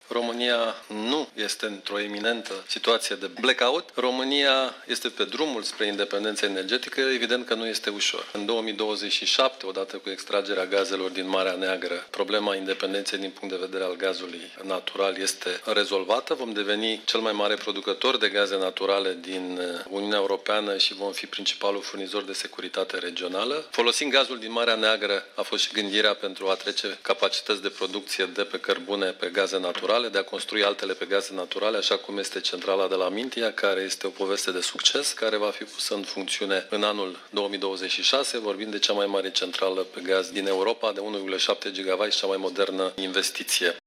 Riscul unei pene de curent majore este extrem de redus în ţara noastră, a declarat, astăzi, la o conferinţă de specialitate, Virgil Popescu, membru în Comisia pentru industrie, cercetare şi energie din Parlamentul European şi fost ministru al Energiei.